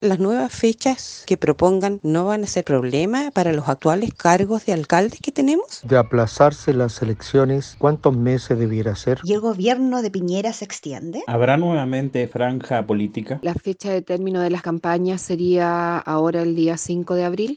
En Radio Bío Bío conversamos con ciudadanos, que nos comentaron sus inquietudes ante la postergación del proceso.